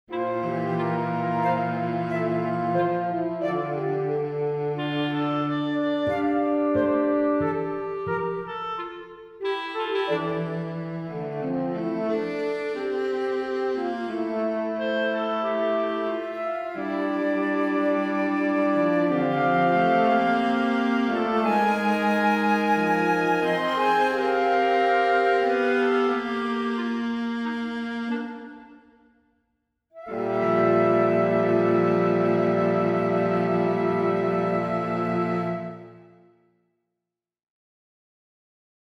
Chamber